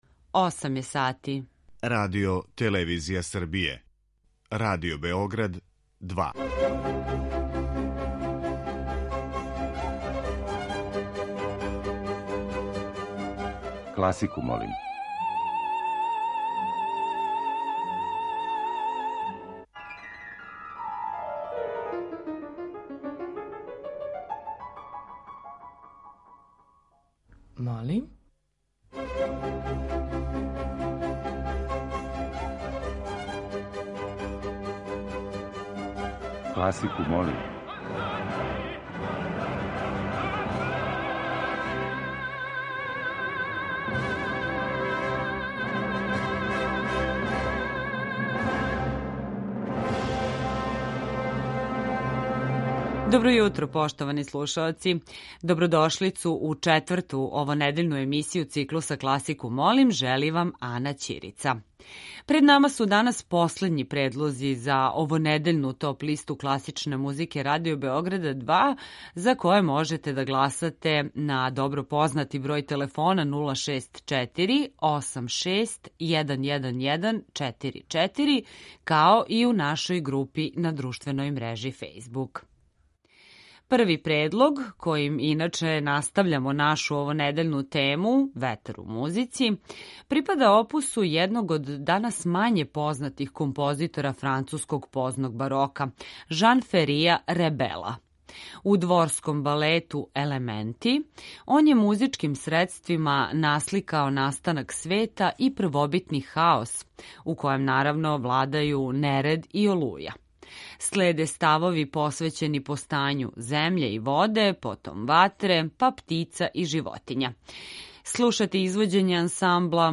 Подсетићемо се остварења која у наслову носе реч „ветар" или тонски сликају дување ветра.